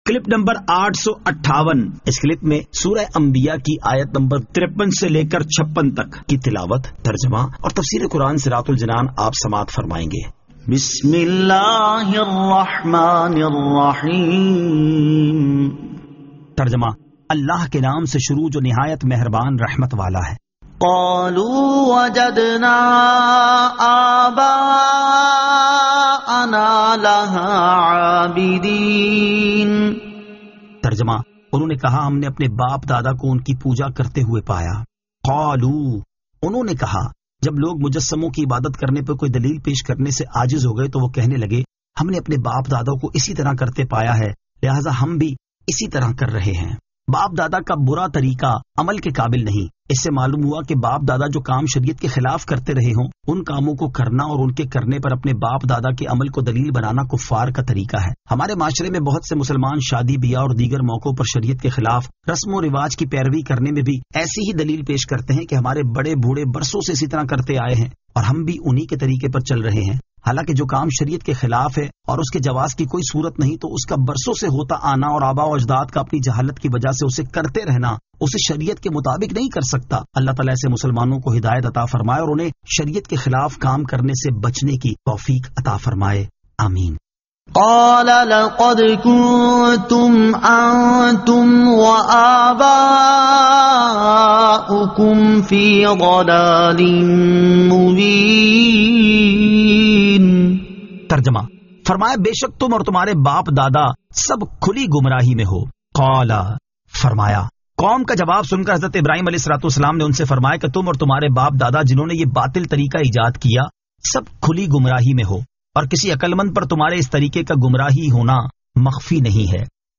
Surah Al-Anbiya 53 To 56 Tilawat , Tarjama , Tafseer